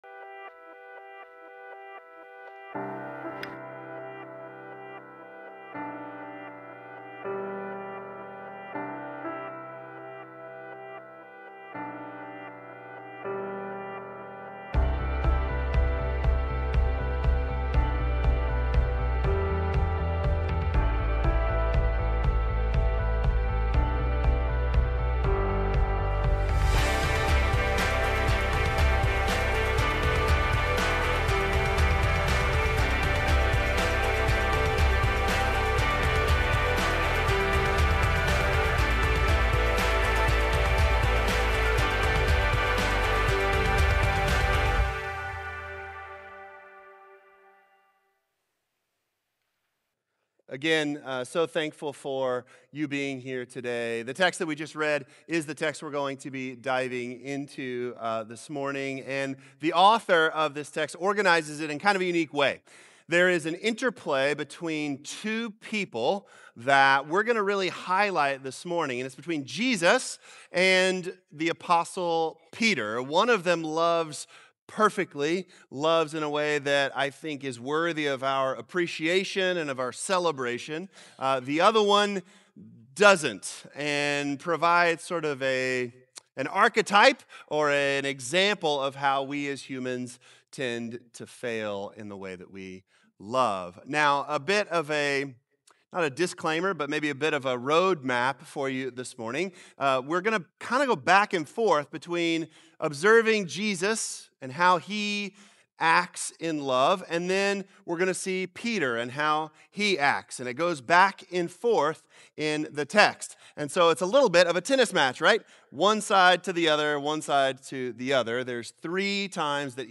True-Love-Sermon-2.15.25.m4a